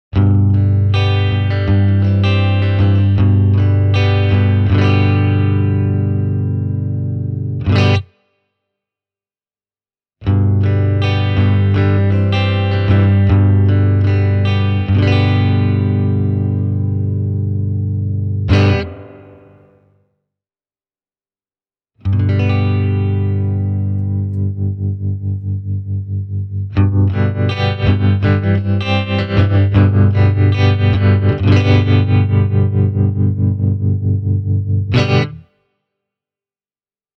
The sound of the short MOD reverb tank is surprisingly dense and complex, and there’s more than enough of it to satisfy Surf Music fans.
Here’s a clip, recorded with a Fender Telecaster, gives you an idea of the Bluetone Black Prince Reverb’s dry tone, as well as its spring reverb and tremolo effects:
bluetone-black-prince-reverb-e28093-dry-reverb-tremolo.mp3